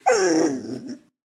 Minecraft Version Minecraft Version snapshot Latest Release | Latest Snapshot snapshot / assets / minecraft / sounds / mob / wolf / grumpy / whine.ogg Compare With Compare With Latest Release | Latest Snapshot
whine.ogg